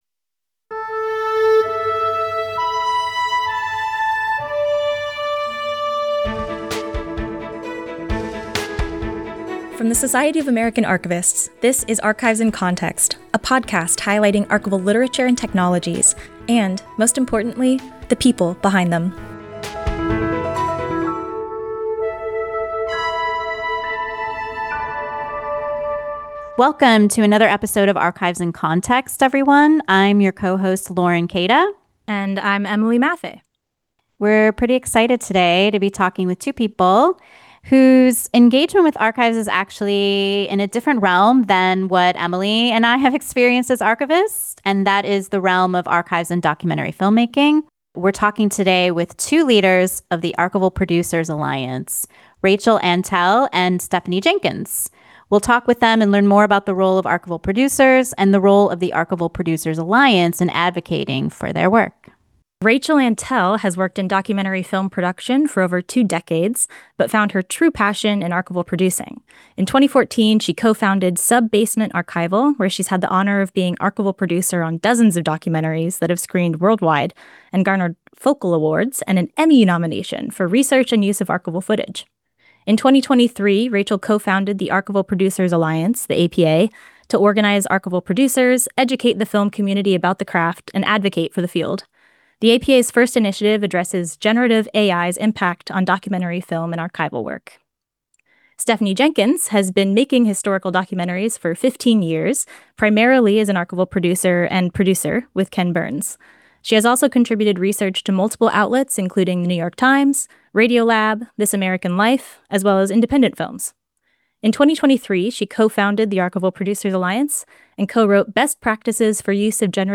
They also talk about the guidelines they wrote for navigating the impact of generative AI on the work of archival producers and on the historical record as a whole. Listen in for a fascinating conversation on the many facets of archival producing, from research and relationship-building to navigating copyright, deadlines, and emerging technologies.